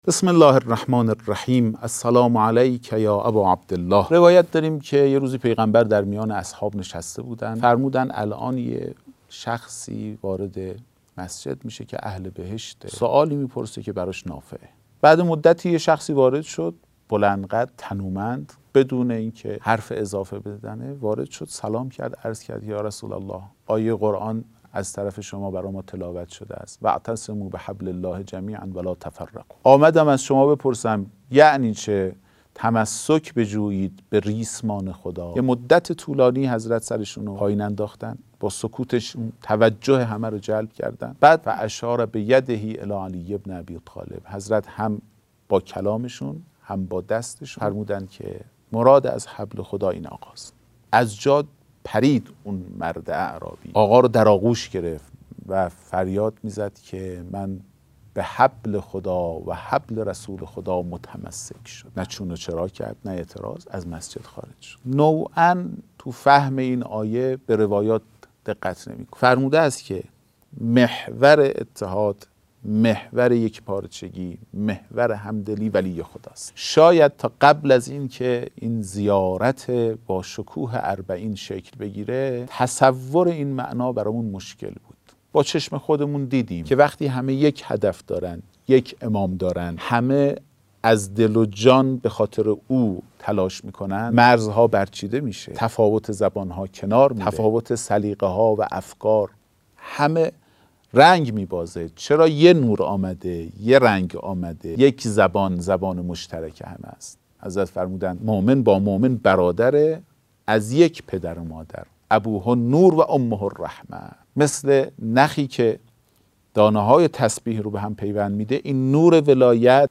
سخنرانی اربعین